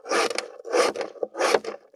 518切る,包丁,厨房,台所,野菜切る,咀嚼音,ナイフ,調理音,まな板の上,料理,
効果音厨房/台所/レストラン/kitchen食器食材